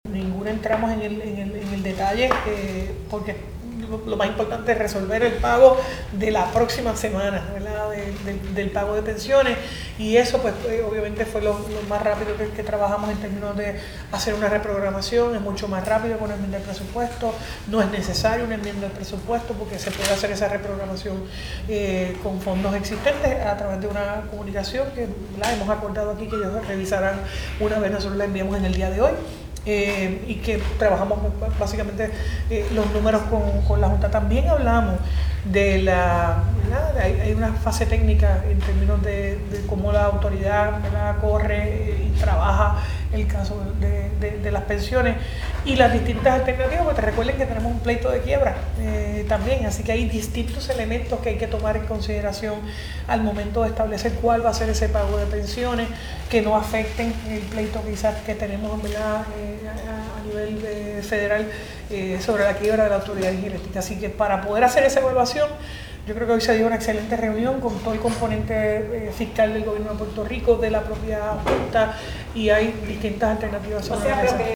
Así que, para poder hacer esa evaluación, yo creo que hoy se dio una excelente reunión con todo el componente fiscal del Gobierno de Puerto Rico, de la propia Junta y hay distintas alternativas sobre la mesa”, indicó la gobernadora en conferencia de prensa.